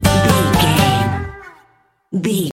Ionian/Major
acoustic guitar
banjo
bass guitar
drums